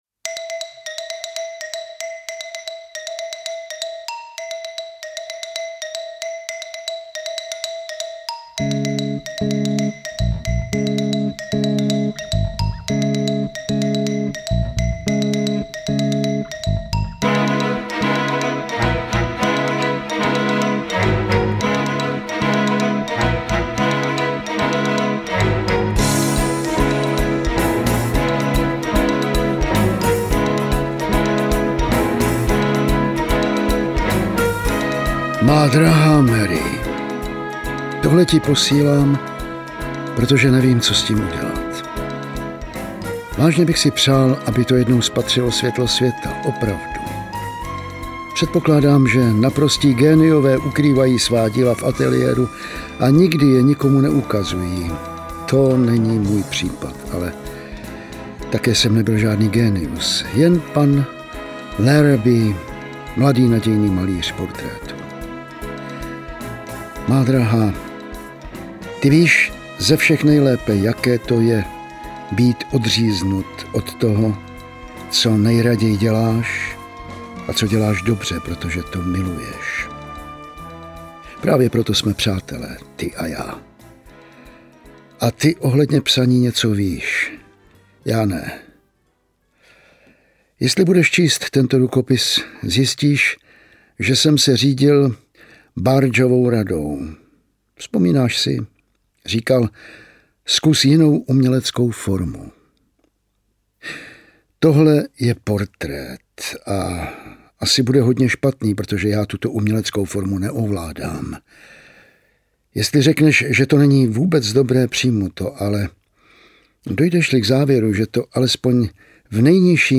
Interpret:  Jan Vlasák